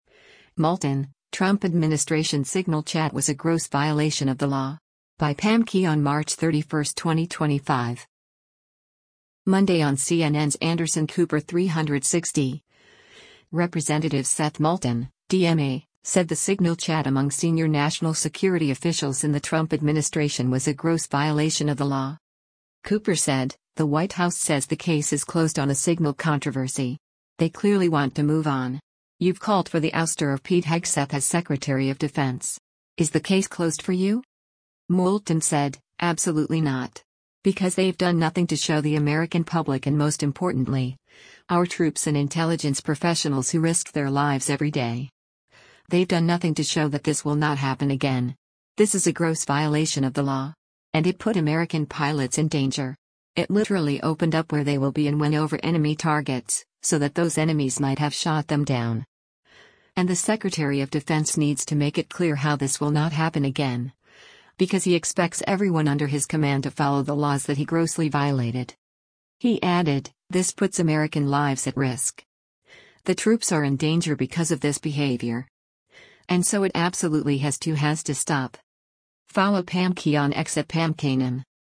Monday on CNN’s “Anderson Cooper 360,” Rep. Seth Moulton (D-MA) said the Signal chat among senior national security officials in the Trump administration was a “gross violation of the law.”